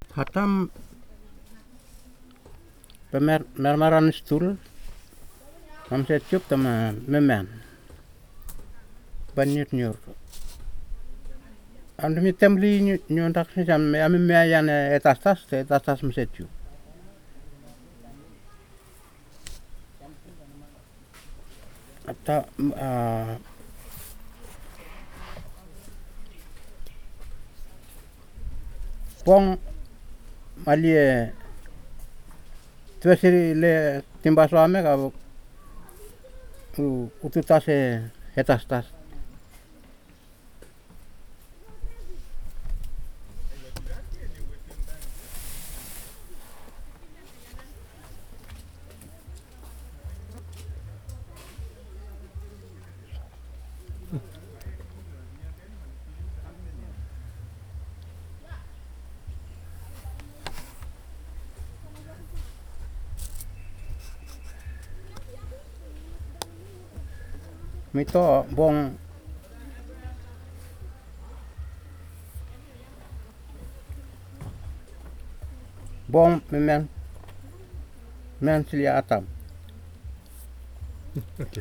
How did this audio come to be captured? Storyboard elicitation of argument structure alternations (Storyboard: The old bench). digital wav file recorded at 44.1 kHz/16 bit on Marantz PMD 620 recorder Emiotungan, Ambrym, Vanuatu